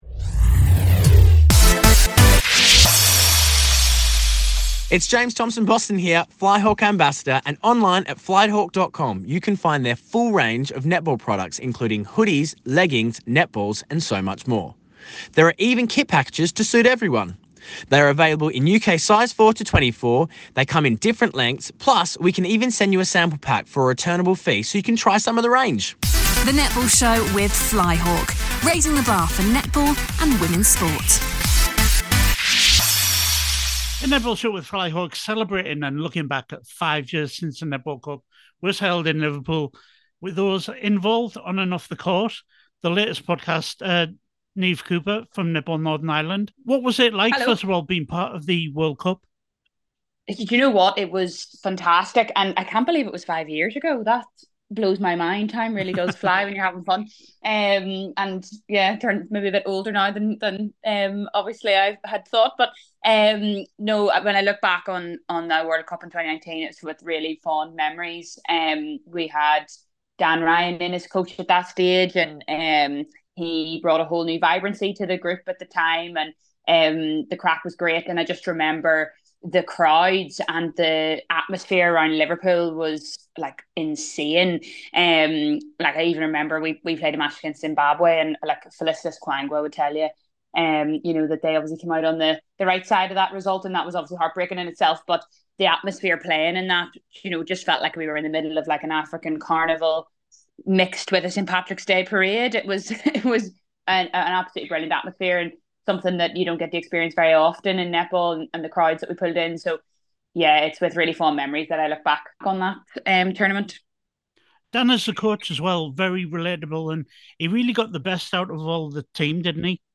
How did this Northern Ireland Warrior feel the games went and what is it like to represent your county on a massive stage? A new interview